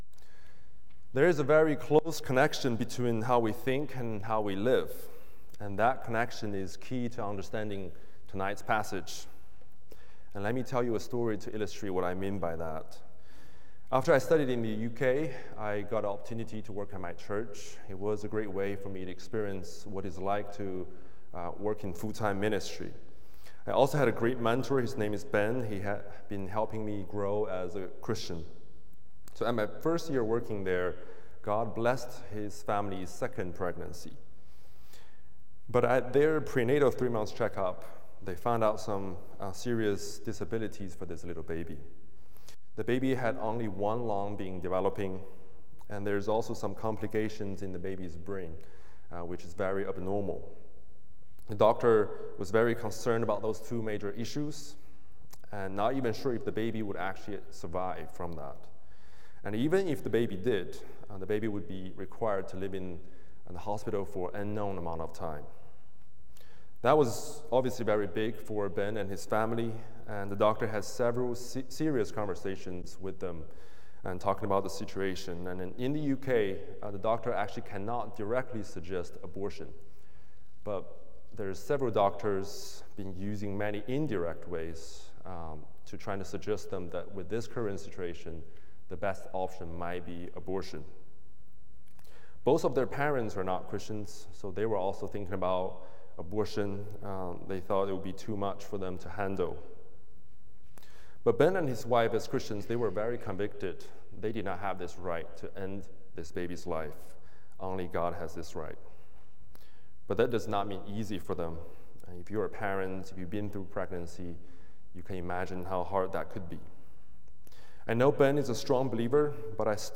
Similar to High Pointe Baptist Church Sermons